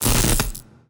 electra_hit.ogg